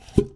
absorb sound
suck2.wav